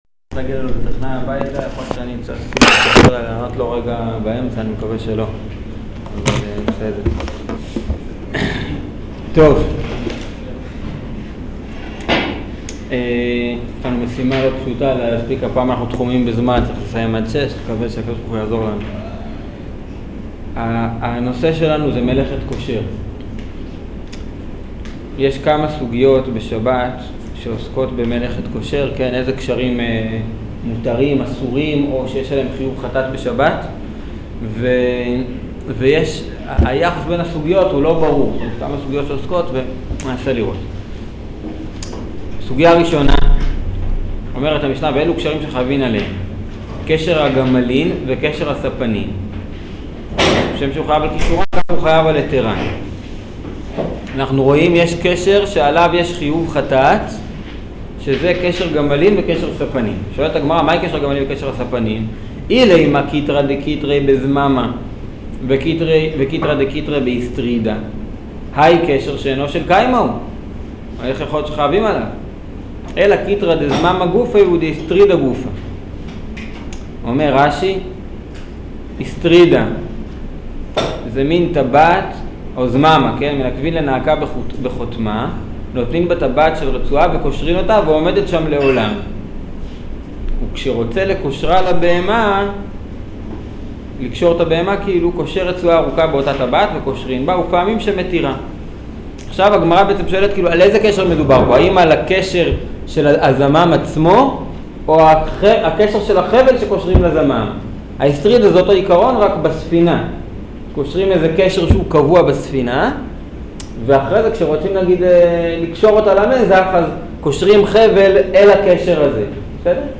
שיעור מלאכת קושר